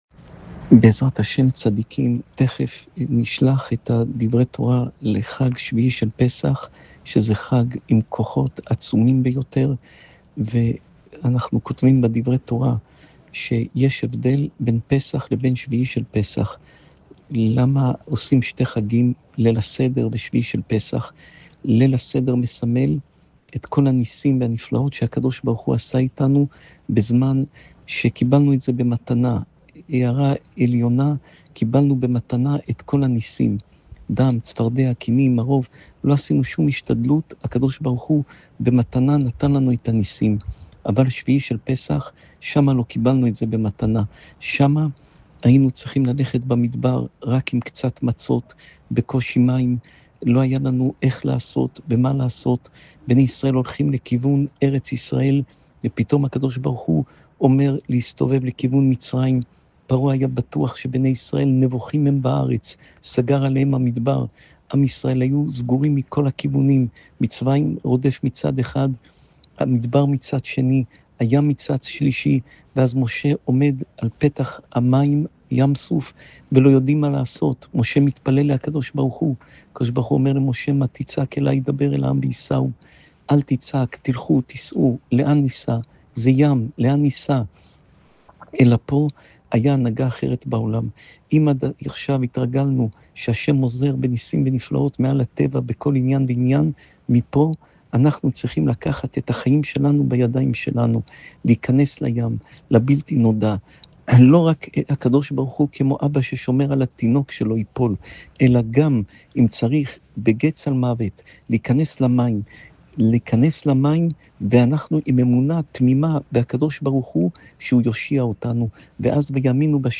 שעור תורה